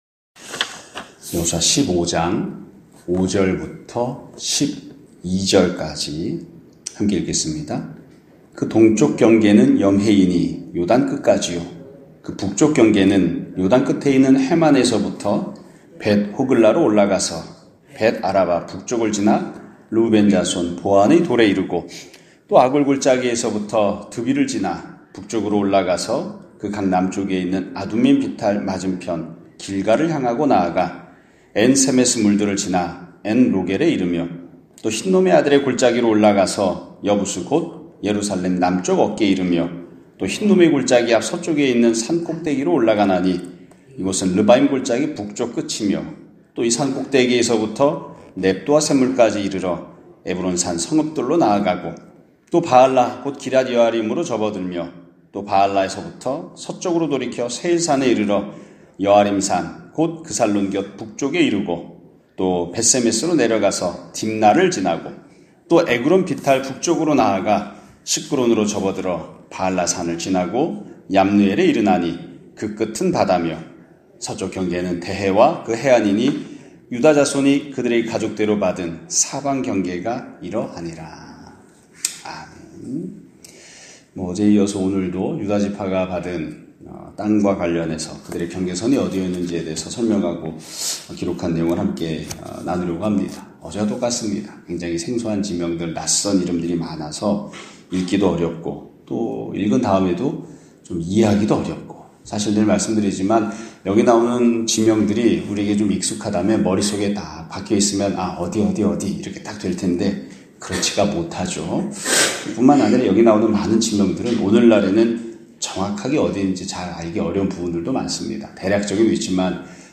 2024년 12월 3일(화요일) <아침예배> 설교입니다.